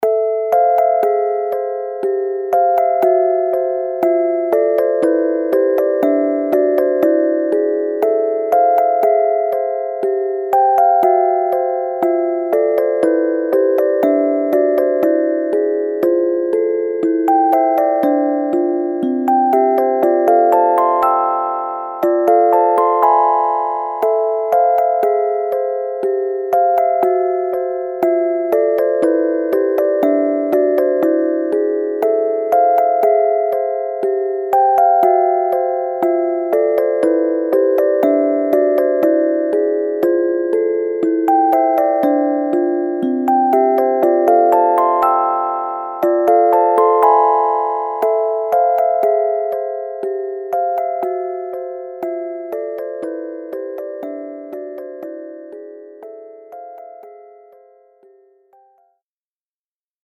詩に散りばめられるような伴奏が特徴的な短いオルゴール曲です